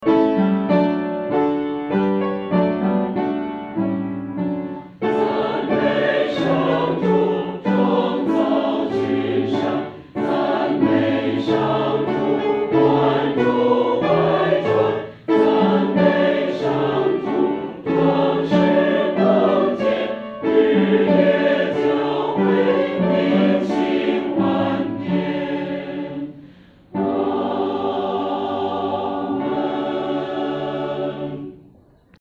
合唱
四声
这首诗的曲调活跃，和声变化色彩丰富；与前面五首赞美诗的和声处理方面很不同，是一首典型的“众赞歌”。